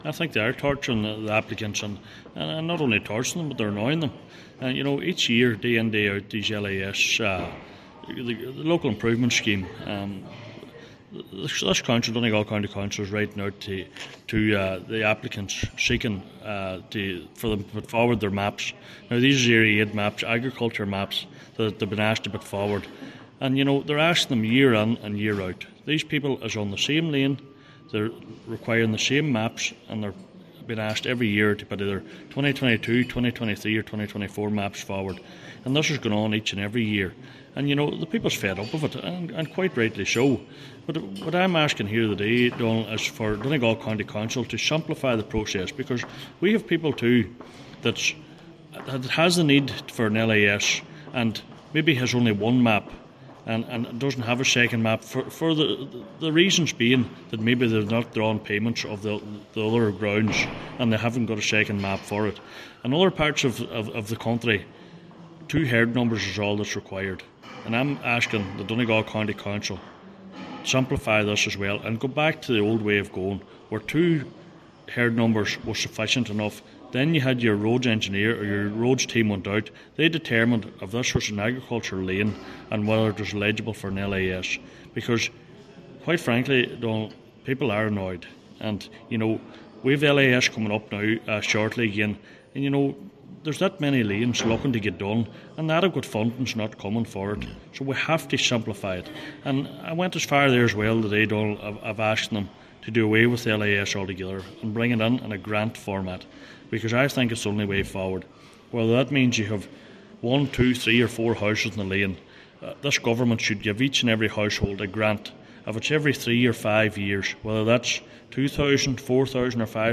That’s the view of Cllr Donal Mandy Kelly, who told a meeting of Letterkenny Milford Municipal District that the county has the third highest LIS waiting list in the country, and the application process needs to be simplified.